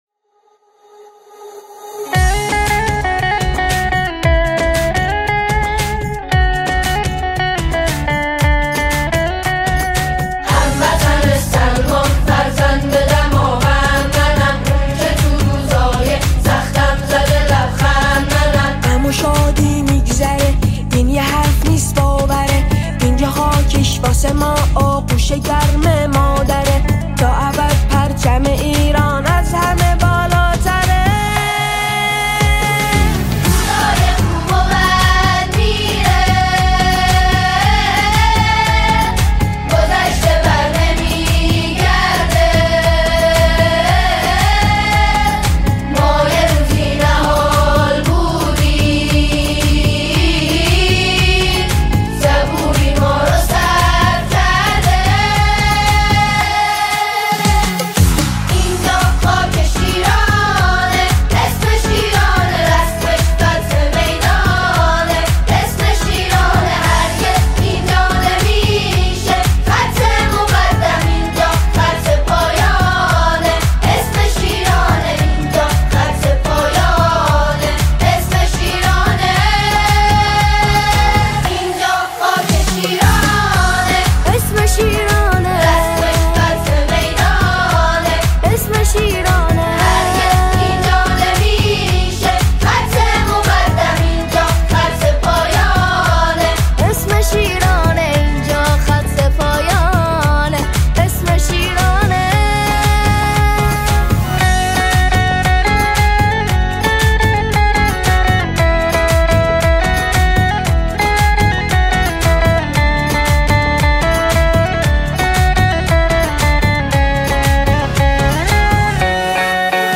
اثری حماسی و الهام‌بخش